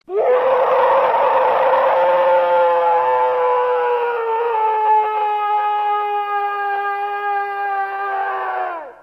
Рёв монстра - Monster roar
Отличного качества, без посторонних шумов.
158_monster-roar.mp3